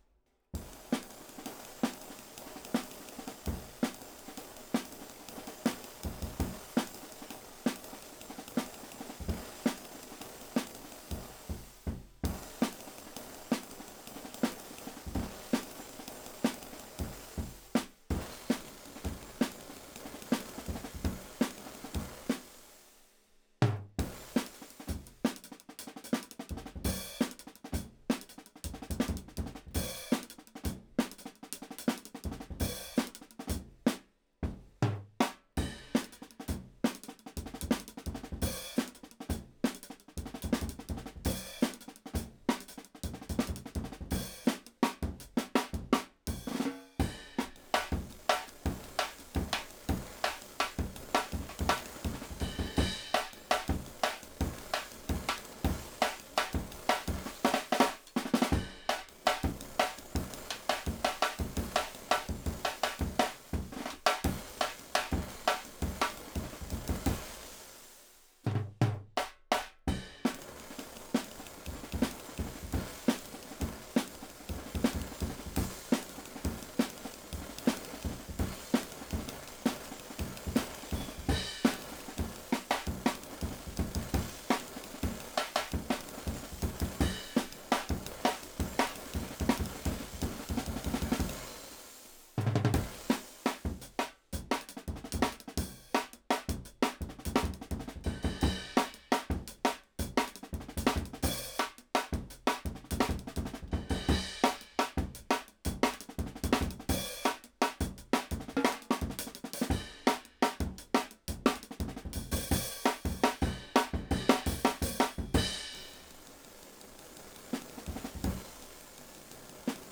Index of /4 DRUM N BASS:JUNGLE BEATS/BEATS OF THE JUNGLE THAT ARE ANTIFUNGAL!!/RAW MULTITRACKS
OH RIDE_1.wav